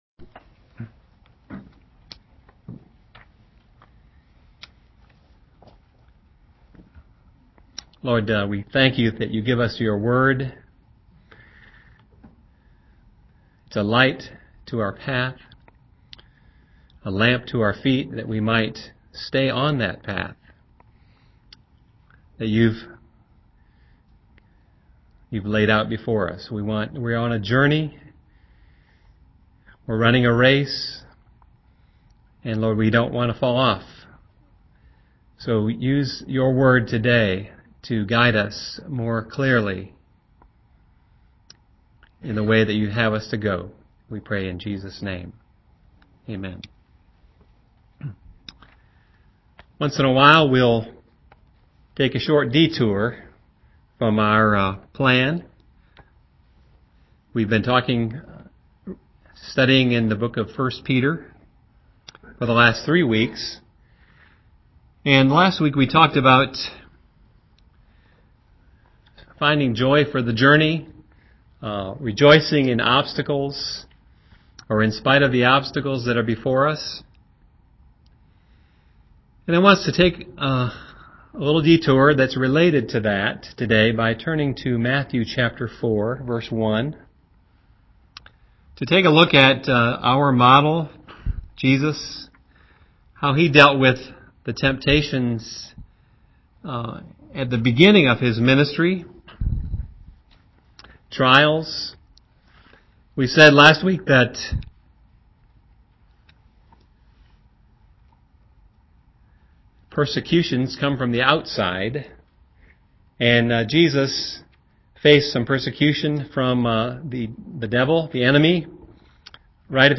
Categories: 2011, Matthew, Sermons, Temptation